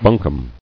[bun·combe]